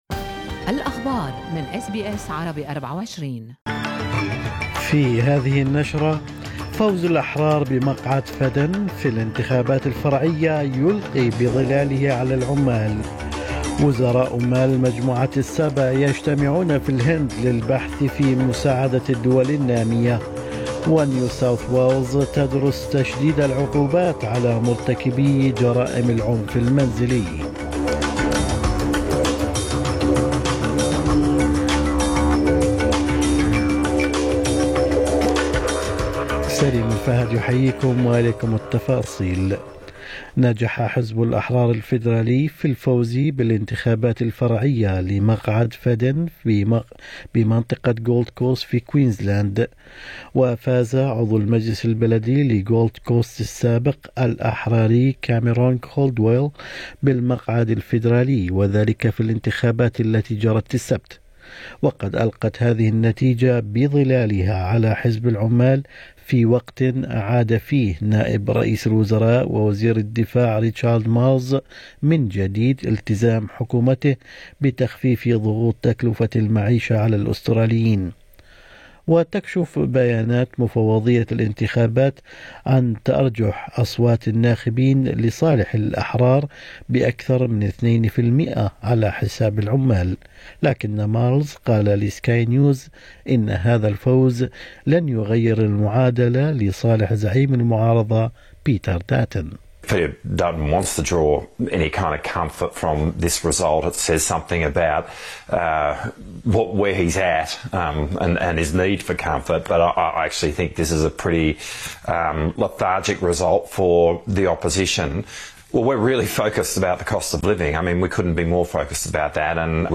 نشرة اخبار الصباح 17/7/2023